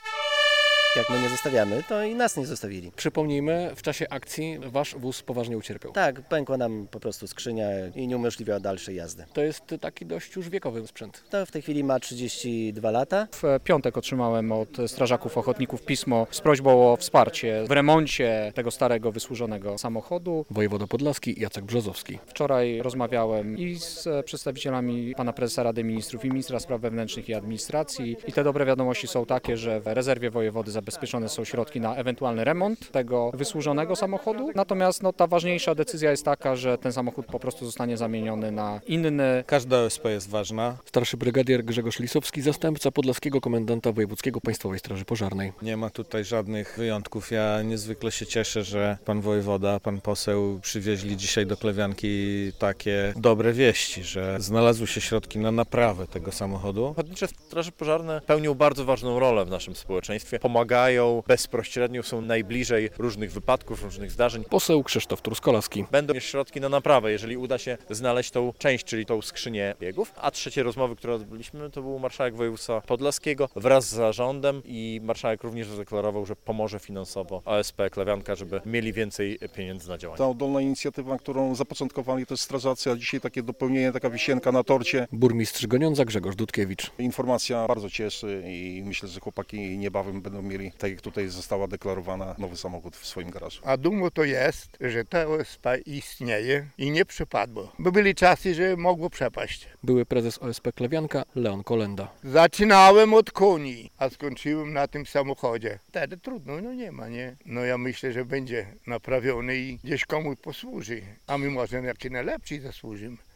Takie informacje przekazał we wtorek (13.05) podczas konferencji prasowej wojewoda podlaski Jacek Brzozowski.